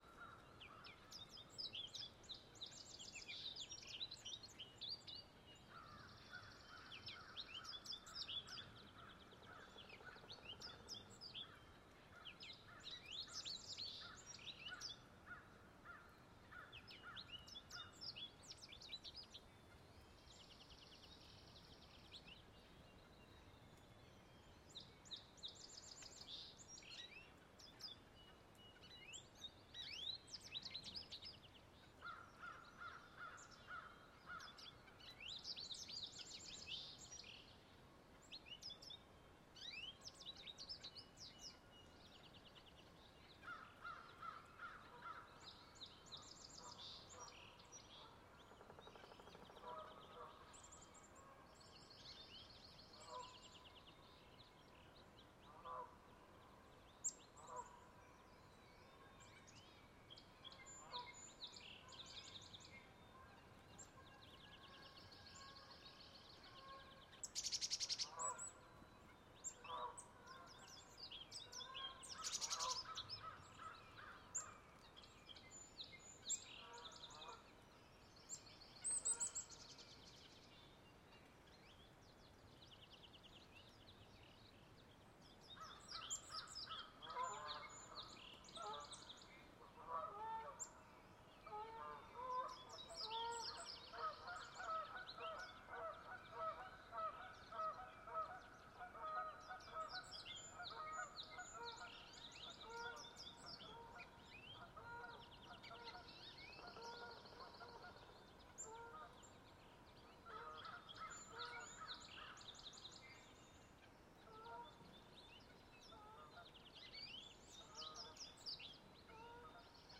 В подборке представлены природные звуки, медитативные мелодии и фоновые шумы для релаксации, работы и сна.
Весеннее утро с птицами и солнцем сквозь листья